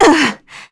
Juno-Vox_Damage_kr_01.wav